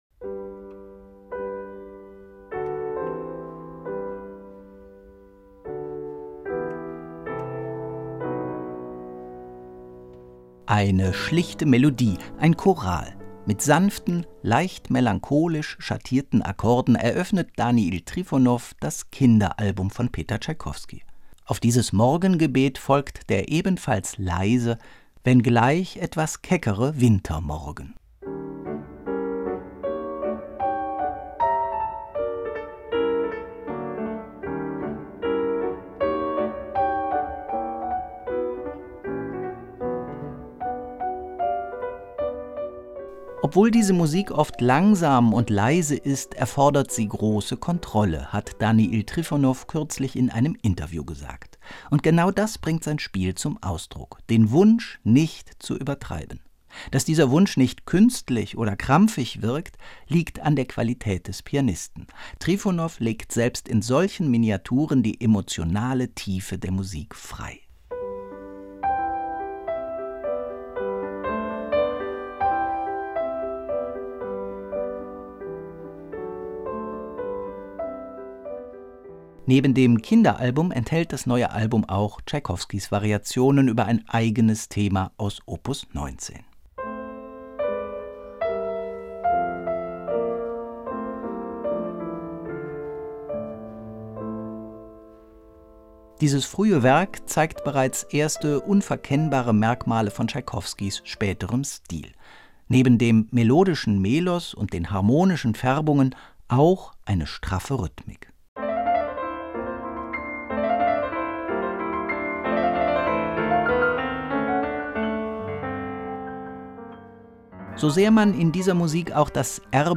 Mit einer schlichte Melodie eines Chorals und sanften, leicht melancholisch schattierten Akkorden eröffnet Daniil Trifonov das „Kinderalbum“ von Peter Tschaikowsky.
Das Scherzo hingegen gerät zu einem Satz voller Kontraste: schwebend-filigran hier, trotzig-lodernd dort.
Hier nun dominieren mehr das Virtuose und Brillante.